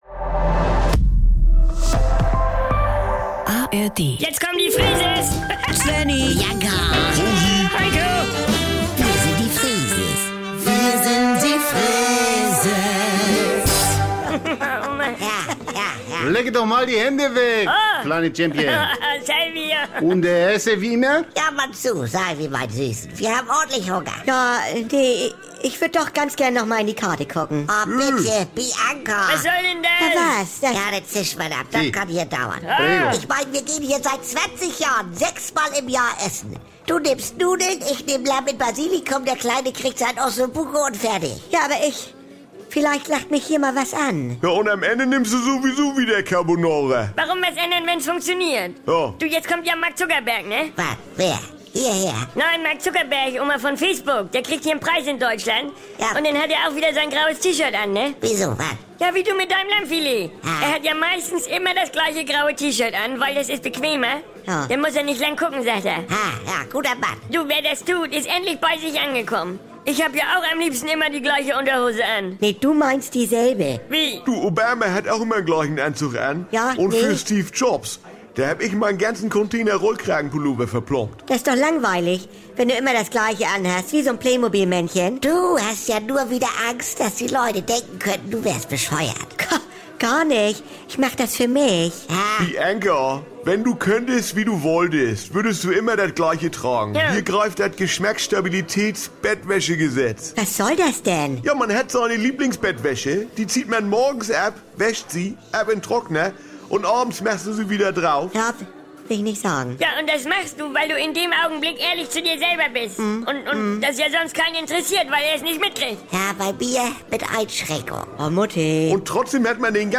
… continue reading 660 Episoden # NDR 2 # Saubere Komödien # Unterhaltung # Komödie